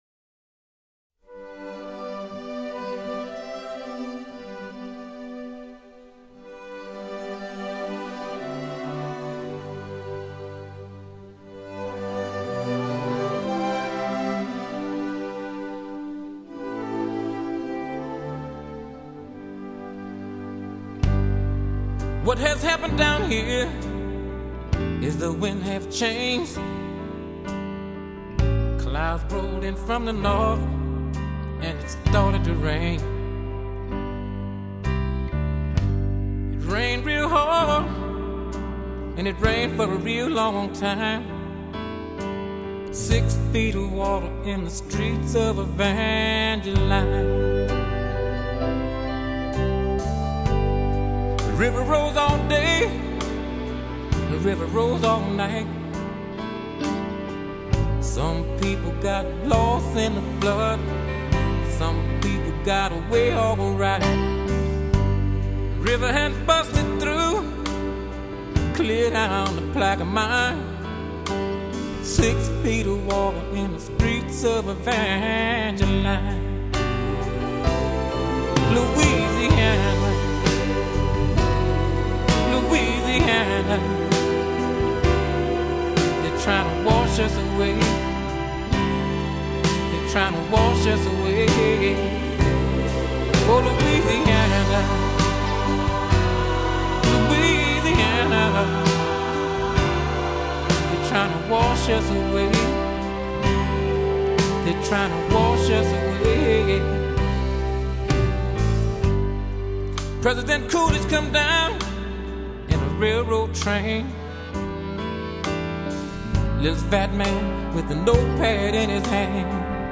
类型：R&B
播放这张唱片的重点和难点都是如何还原出录音中既透明又饱满的声音，另外像真度极高的音场、细节等，也是要留意的方面。